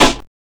Snares
GVD_snr (25).wav